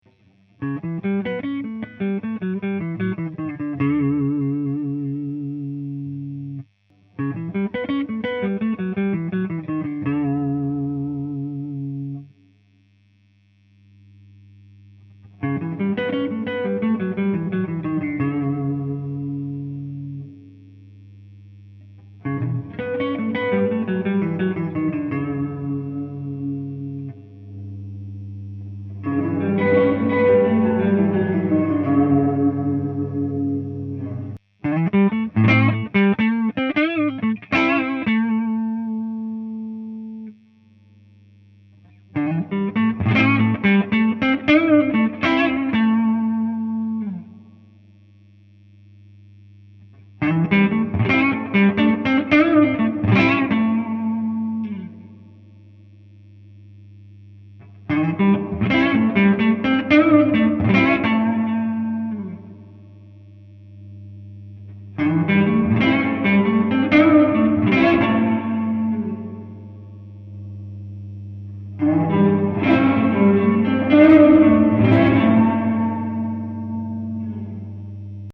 Il reste encore du motorboating probablement du à un filtrage insuffisant de l'alim sur le preamp, à modifier courant janvier.
En attendant, voici un petit sample à différents niveaux de reverb : 2 riffs différents, pour chaque on commence sans reverb, et on augmente petit à petit jusqu'à fond.
Quand la réverb est à fond c'est l'effet cathédrale :lol: Au moins elle en a sous le pied.
Sinon question son, je la trouve assez bien défini — hormis quand c'est à fond ou là c'est vraiment trop à mon goût.
Elle sonne vraiment bien cette reverbe !
reverb.mp3